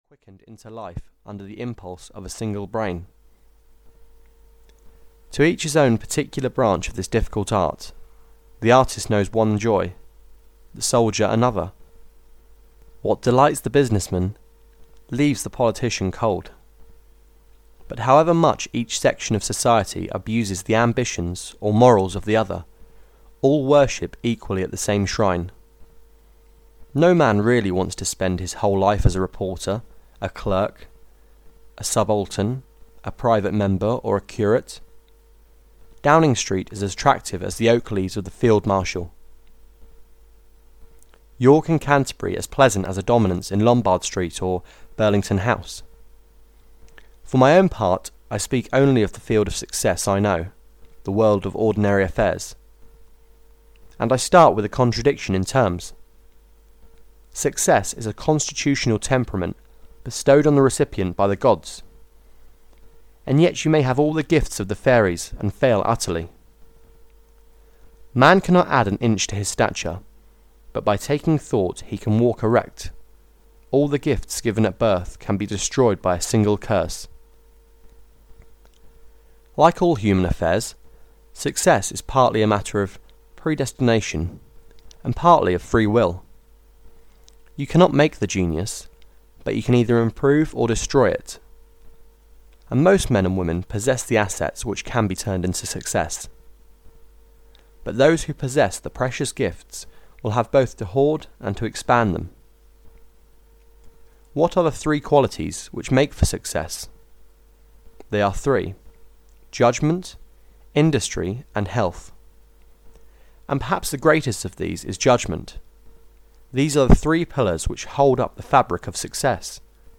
Success (EN) audiokniha
Ukázka z knihy